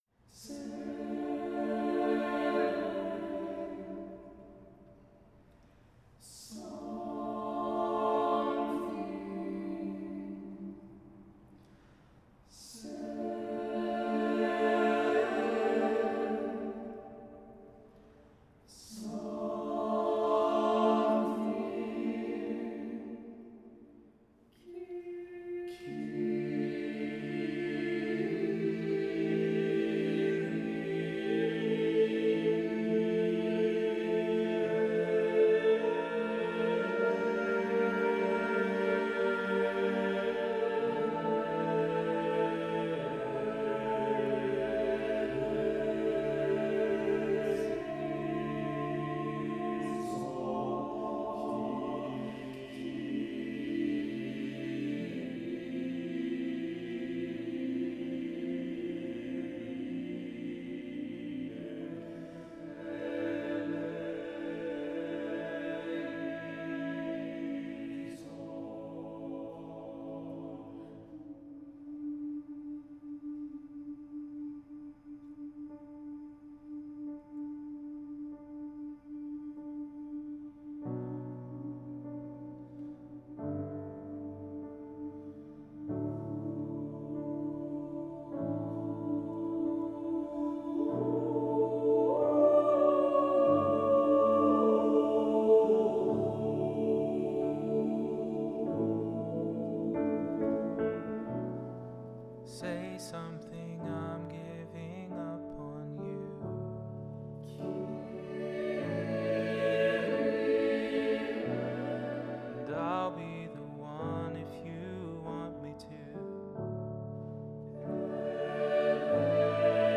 I made this arrangement for the 2021 Good Friday service at St. Andrew.
Because we were still masking and distancing, all parts were recorded separately…which made it extra challenging.